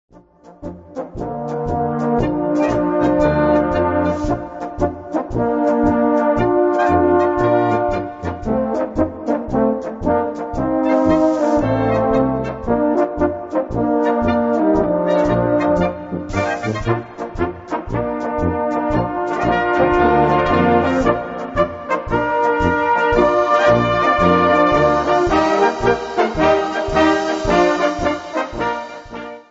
Gattung: Polka Böhmisch
Besetzung: Blasorchester